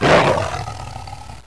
roar2.wav